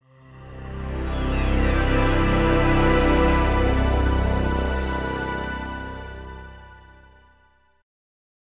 Звуки приветствия Windows